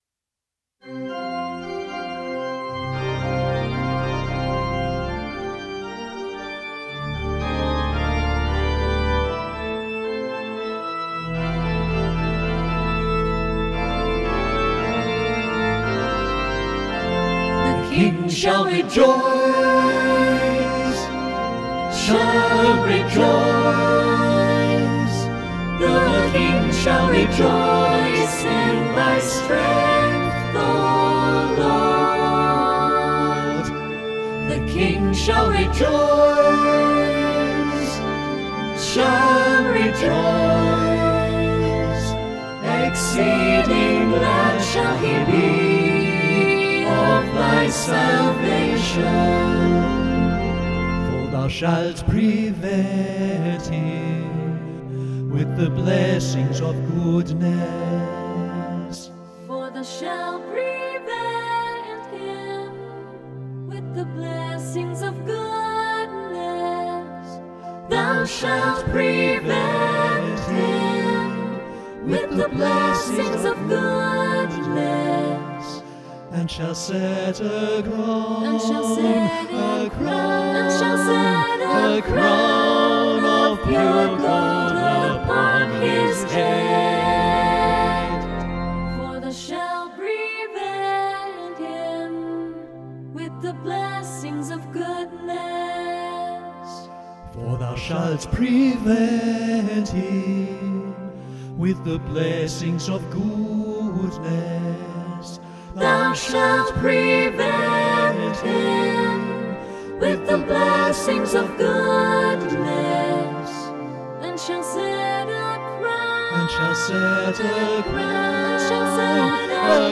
suitable for a parish choir.